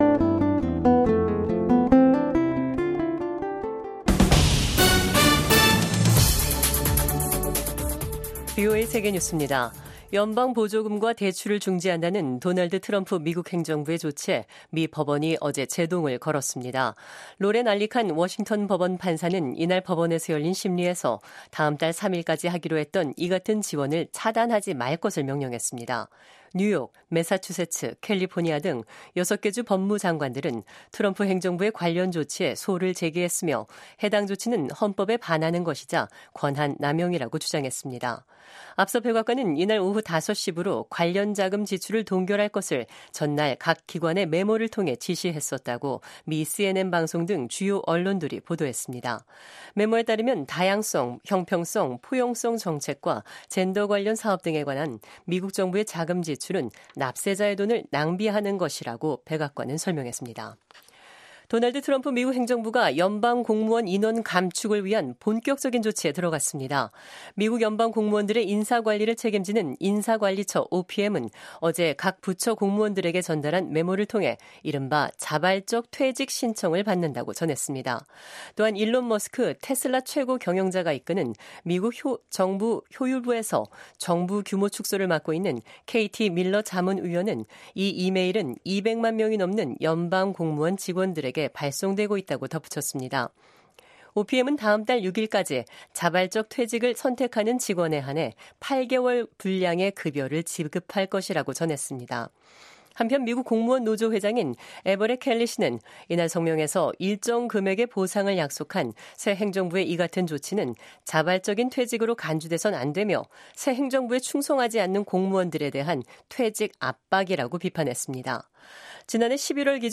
VOA 한국어 간판 뉴스 프로그램 '뉴스 투데이', 2025년 1월 29일 3부 방송입니다. 미국 백악관은 ‘북한의 완전한 비핵화’가 여전히 트럼프 행정부의 목표라고 확인했습니다. 도널드 트럼프 대통령이 미국의 차세대 미사일 방어 시스템 개발을 촉구하는 행정명령에 서명했습니다. 김정은 북한 국무위원장은 핵 대응 태세를 무한히 강화한다는 입장을 거듭 밝혔습니다.